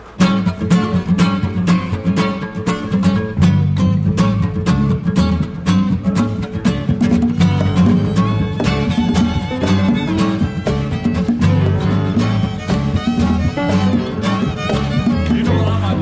Musical Intermission